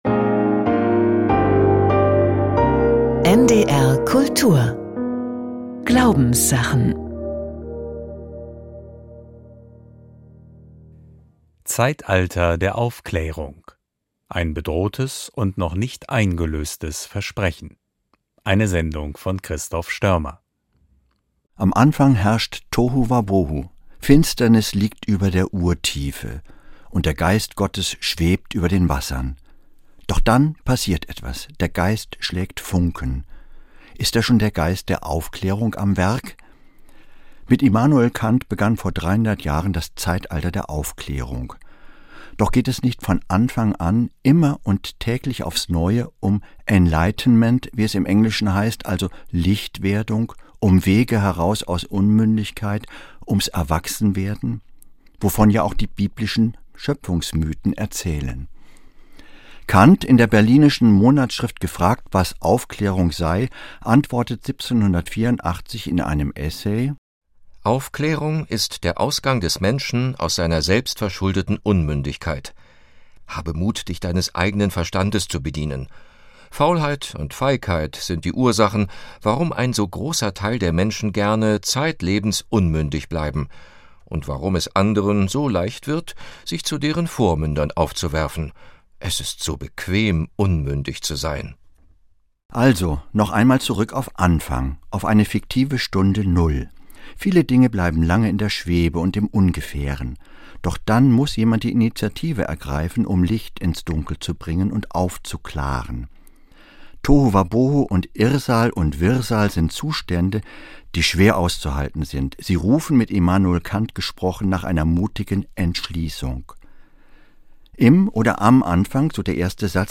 Kinderhörspiel: Mein Freund Otto, das wilde Leben und ich - 15.09.2024